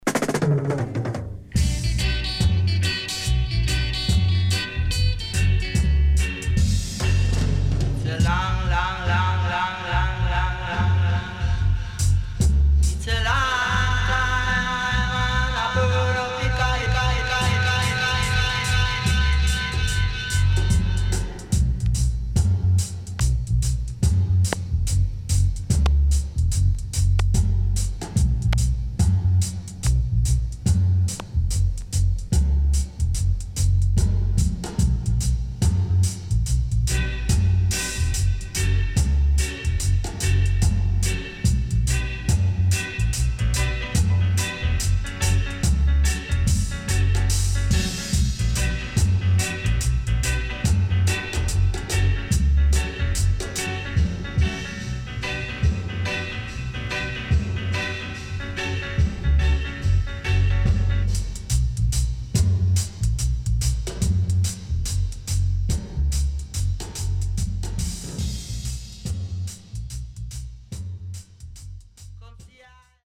哀愁系.渋Vocal